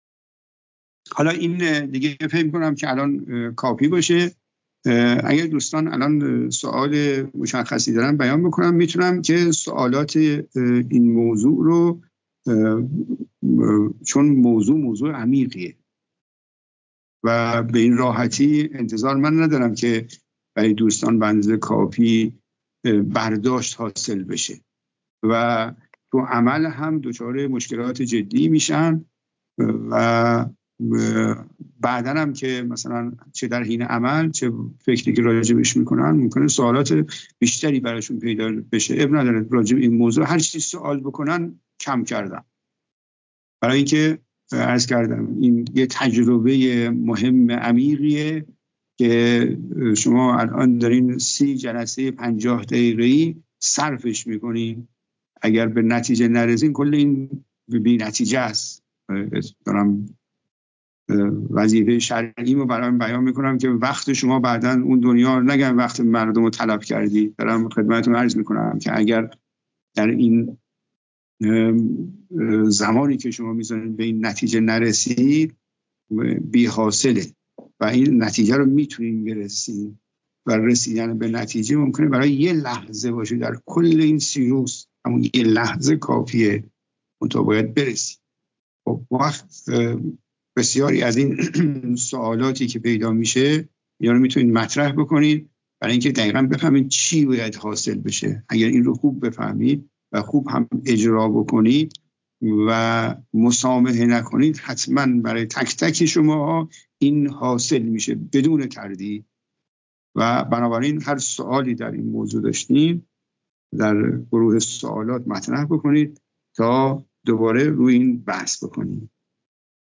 متن : پرسش و پاسخ